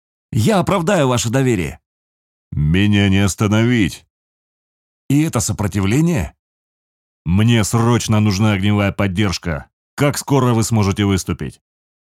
Микрофоны: RODE NTK ; OKTAVA 319 Предусиление: DBX-376 Tube Channel Strip Звуковые интерфейсы: M-Audio ProFire 610, Focusrite Scarlett 2i2 DAW : Logic Pro X